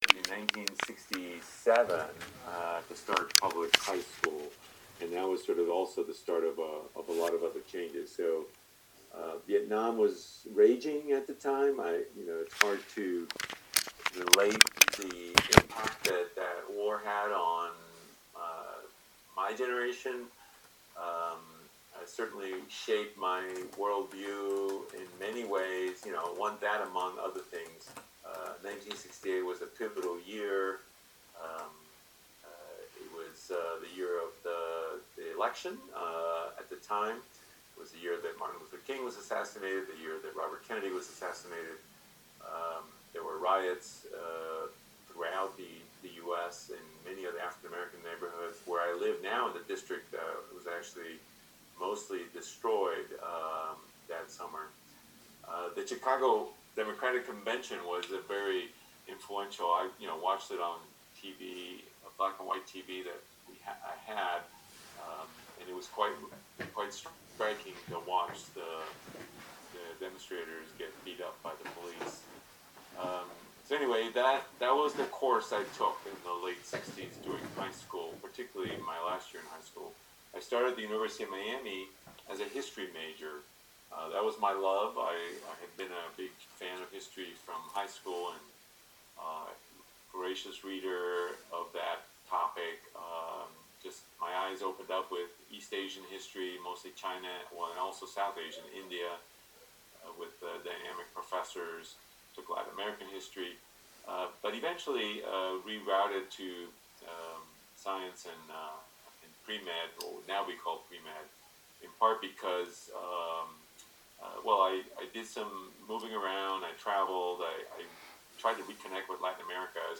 MAC Workshop with Dr. Eliseo Perez-Stable
Date: Wednesday, September 23, 2020, Time: 11am – 12pm ET Location: Virtual, via Zoom Cost: FREE!